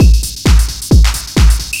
DS 132-BPM A6.wav